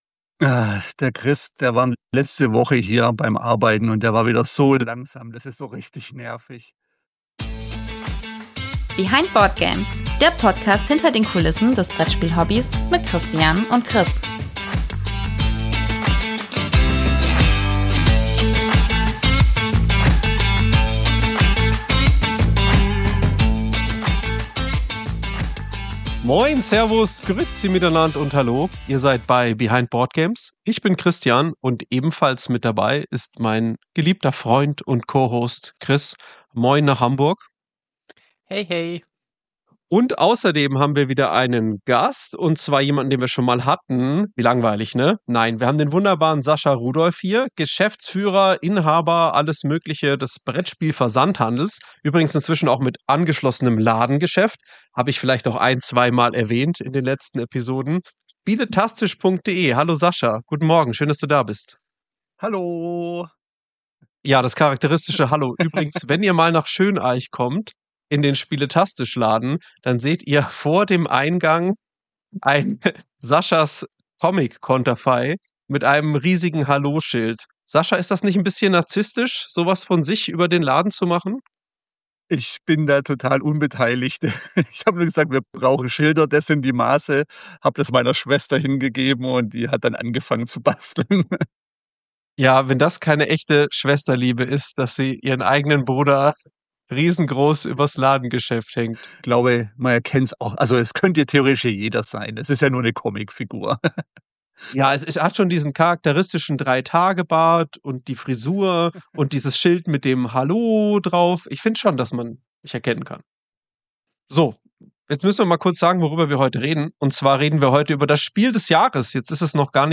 Außerdem dabei: Beiträge von vielen wunderbaren Menschen aus der Spielebranche mit ihren Tipps. In diesem ersten Teil hört ihr unsere Tipps zum Kennerspiel des Jahres.